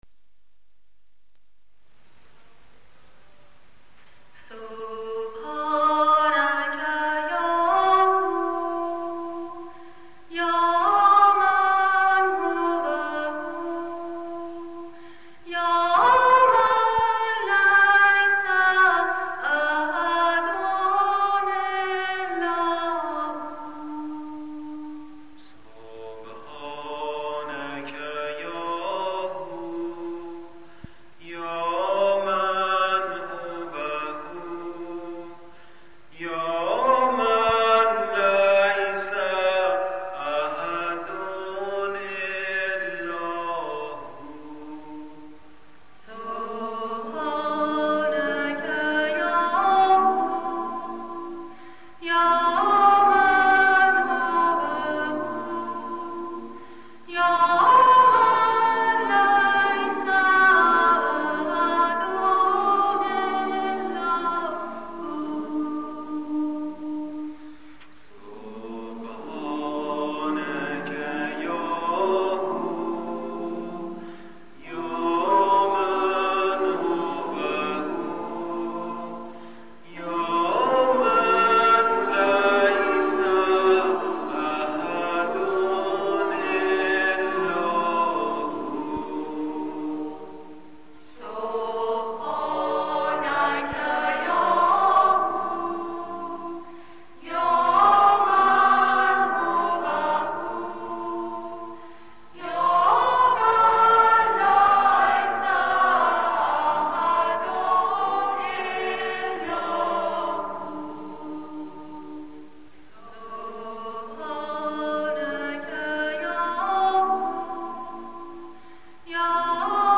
اذکار عربی